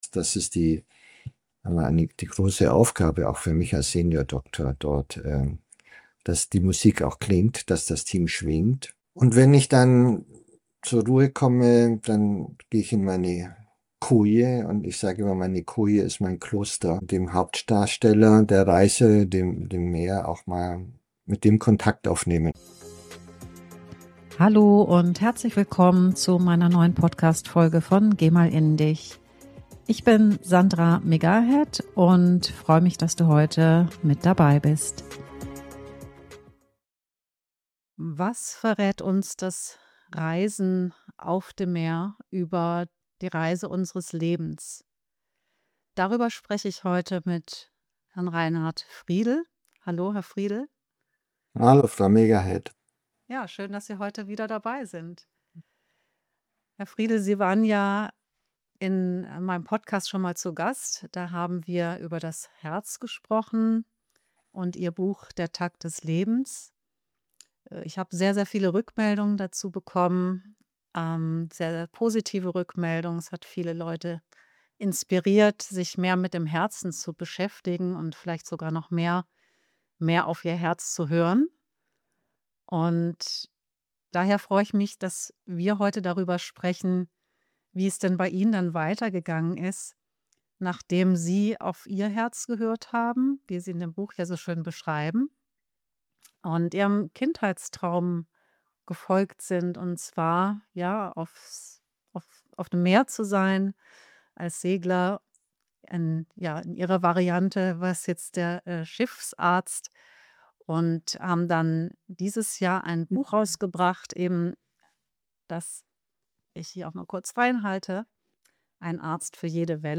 Ein ruhiger Deep Talk über Balance, Verantwortung und das Auftanken auf Reisen, mit dem Meer als stiller Begleiter. Ein tief gehendes Gespräch über Verantwortung, Nähe und Abstand, und darüber, wie das Meer helfen kann, wieder bei sich anzukommen.